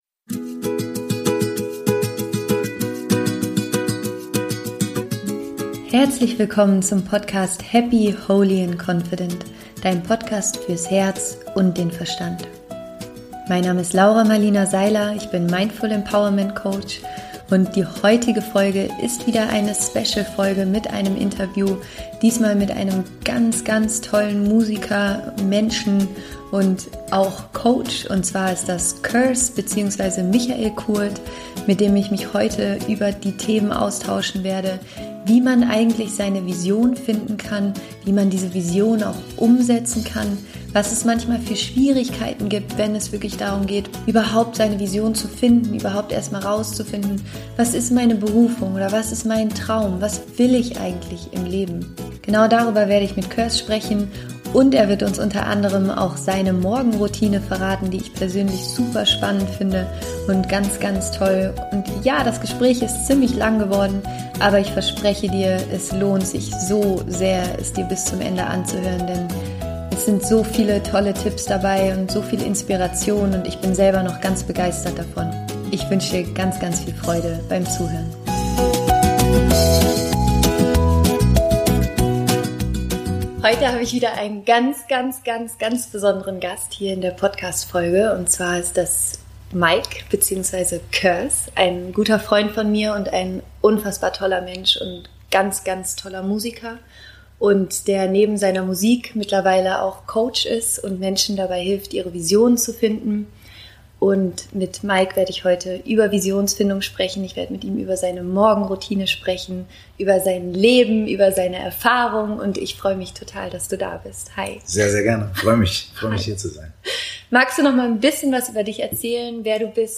Wie finde ich meine Lebensvision? - Interview Special mit Curse